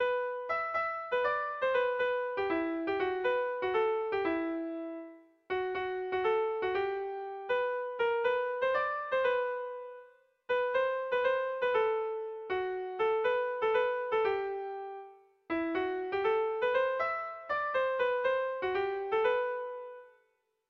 Air de bertsos - Voir fiche   Pour savoir plus sur cette section
Irrizkoa
Zortziko txikia (hg) / Lau puntuko txikia (ip)
ABDE